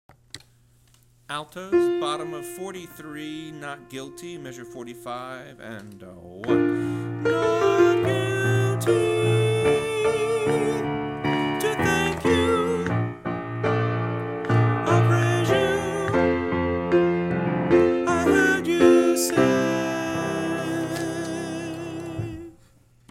Not Guilty individual voice parts